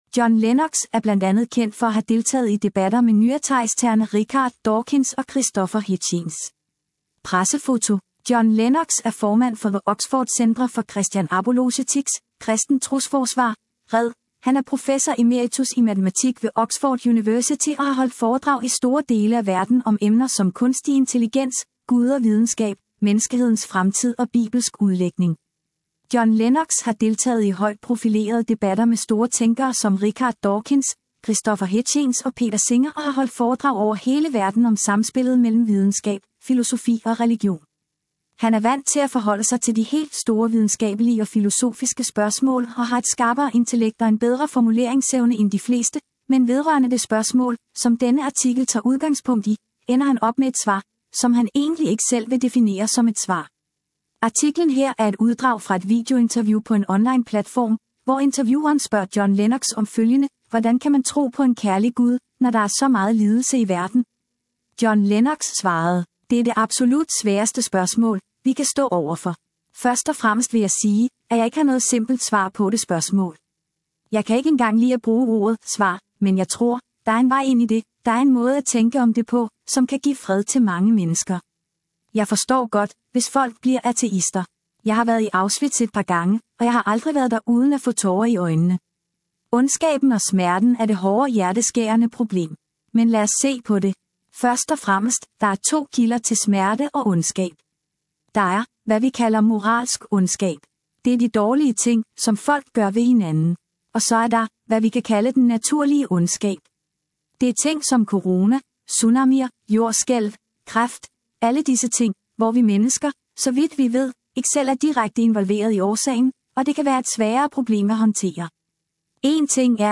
Artiklen her er et uddrag fra et videointerview på en online platform, hvor intervieweren spørger John Lennox om følgende: Hvordan kan man tro på en kærlig Gud, når der er så meget lidelse i verden?